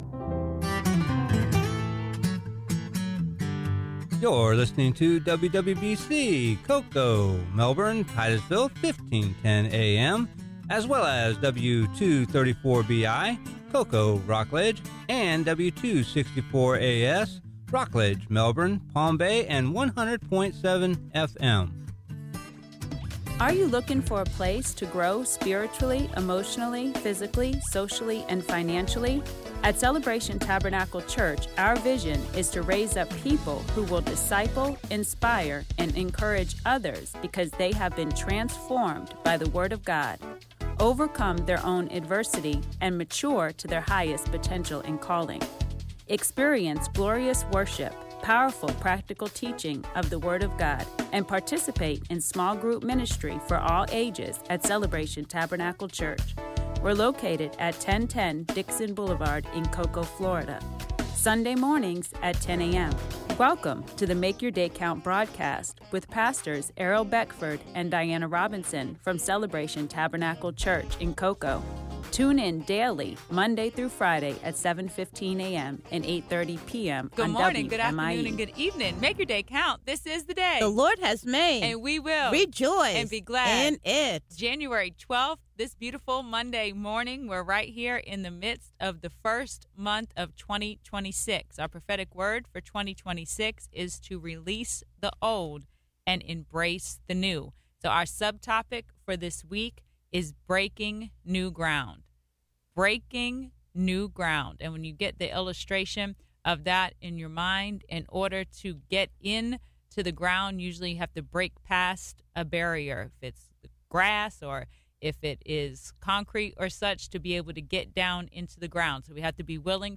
Sermon: